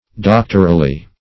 doctorally - definition of doctorally - synonyms, pronunciation, spelling from Free Dictionary Search Result for " doctorally" : The Collaborative International Dictionary of English v.0.48: Doctorally \Doc"tor*al*ly\, adv.